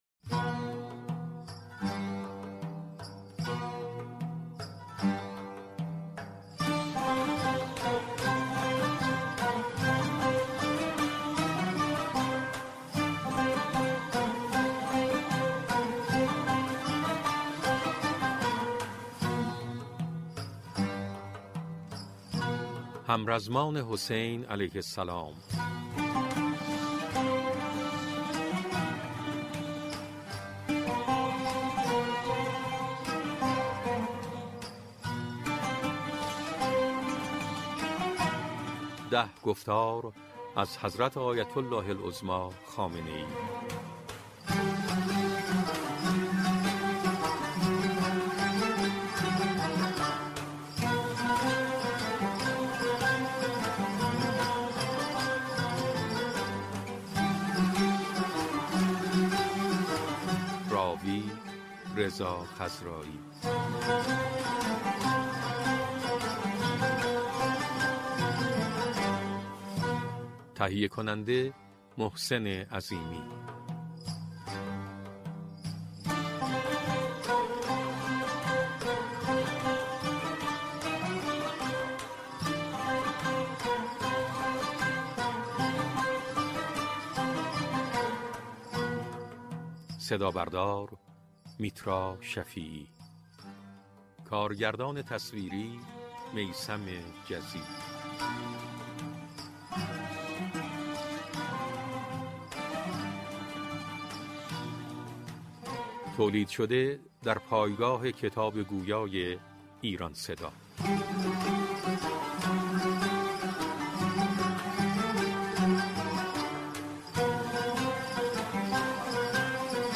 کتاب «همرزمان حسین(ع)» مجموعه‌ سخنرانی‌های حضرت آیت‌الله خامنه‌ای در سال 1351 در هیئت انصارالحسین(ع) تهران است که به تحلیل رسالت و مبارزات سیاسی ائمه(ع) می‌پردازد.